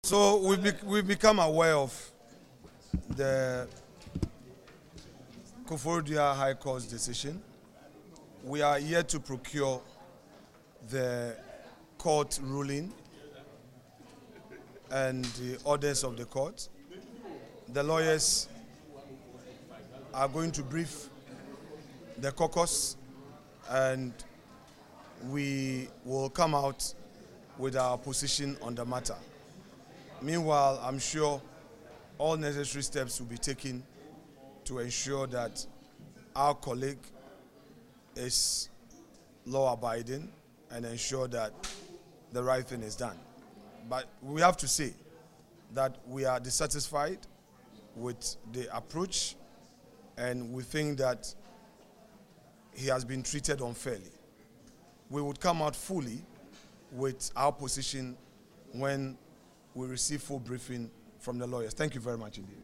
Addressing journalists in Parliament on Wednesday, February 19, the Minority Leader, Alexander Afenyo-Markin, described the court’s decision as unfair and assured that the caucus would take the necessary legal steps to support their colleague.